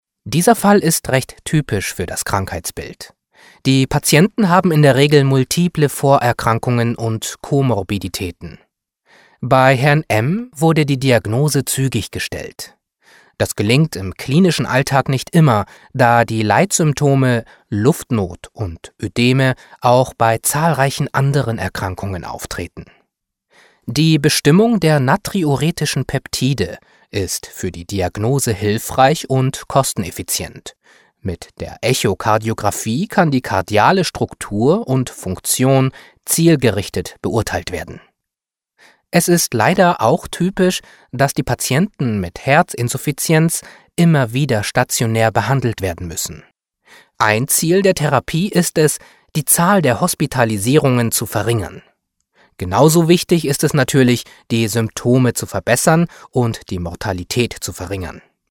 E-Learning „Herzinsuffizienz“